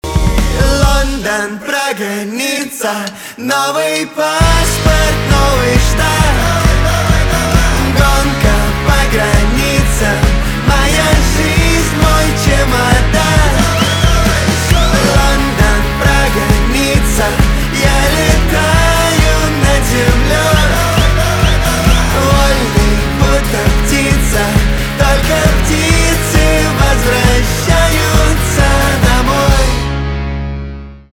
русский рок
барабаны , гитара
свист